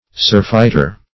Surfeiter \Sur"feit*er\